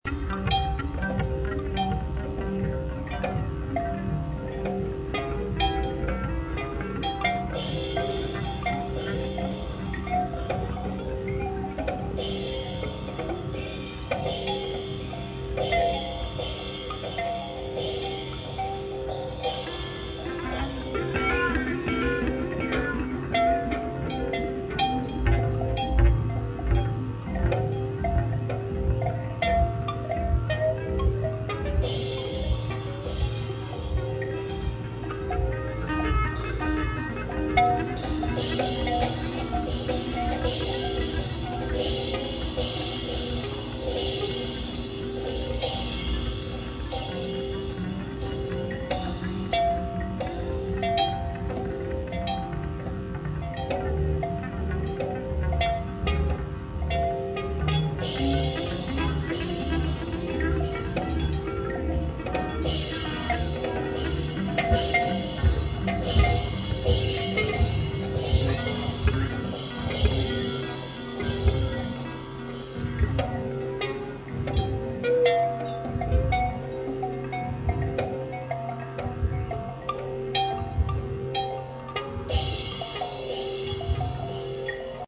Performed live for the first time since 1990
Audio clip from the performance